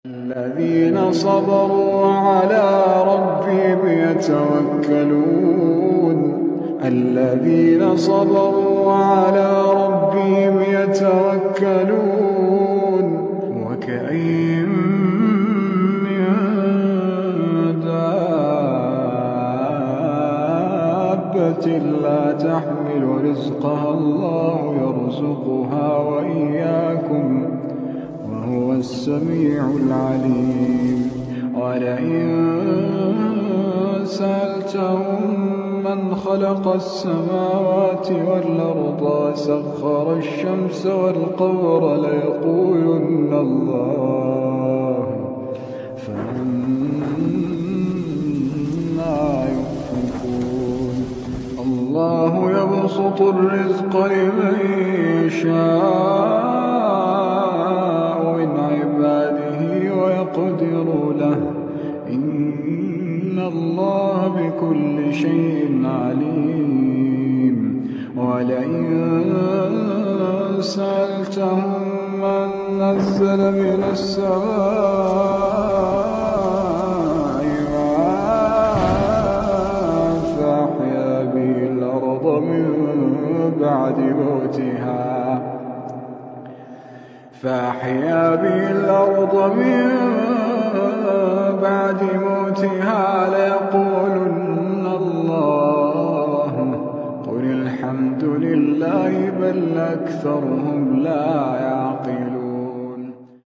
qari abdul rahman mossad tilawat.